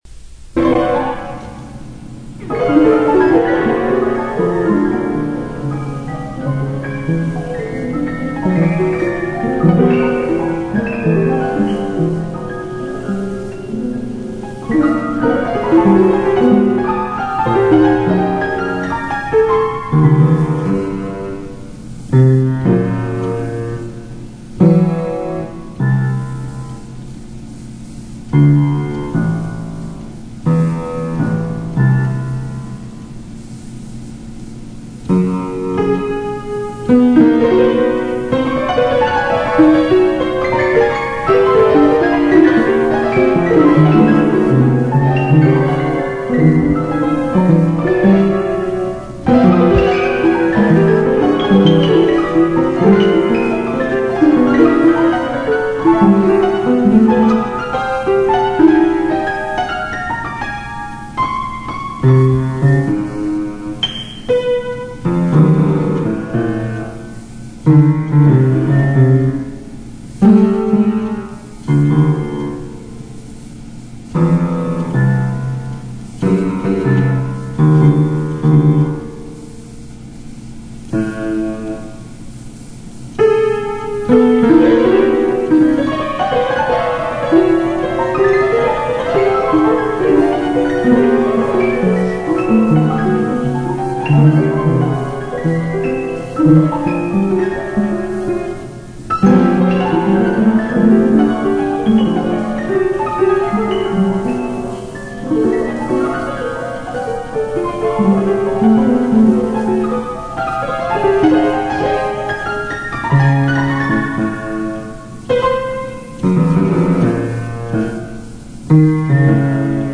Inspired by the structures set up within the genetic code, she has taken these patterns and has uniquely made them create their own music by transposing them literally onto a player piano roll.